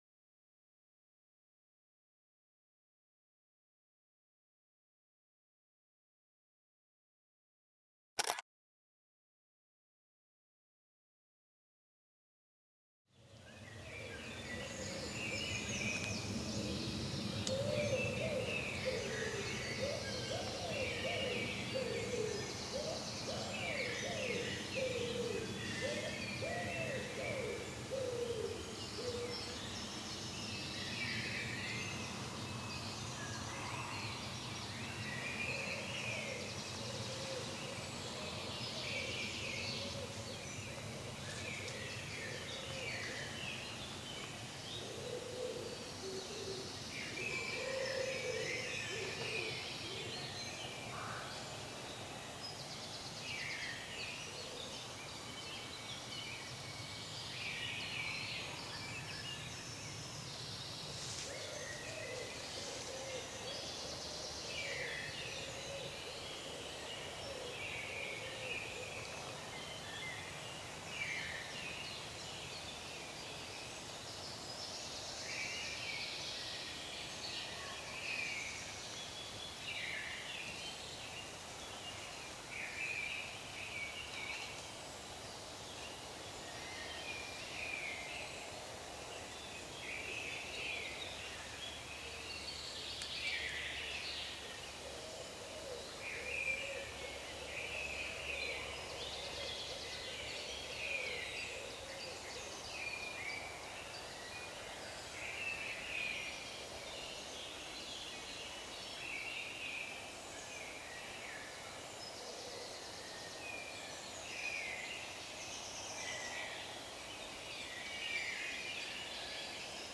PERFEKTE ENTSPANNUNGSQUELLE: Wasserklänge-Ruhequelle im Wald